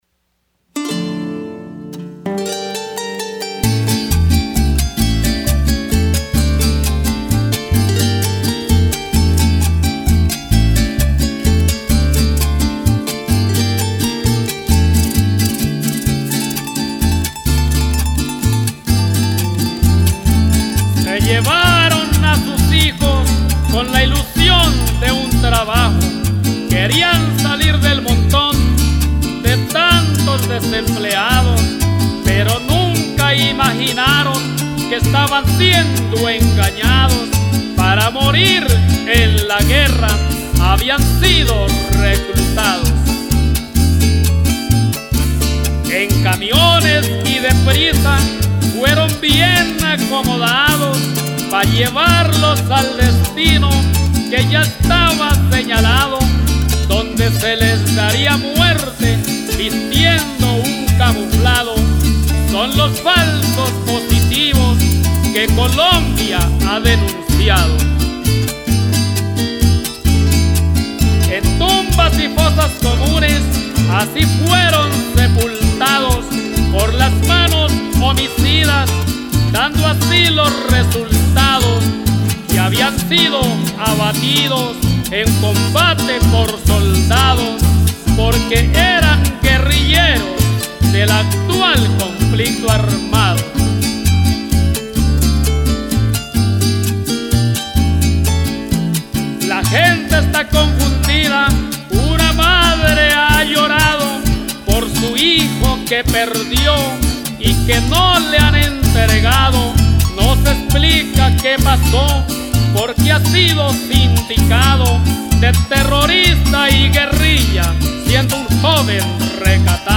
Canción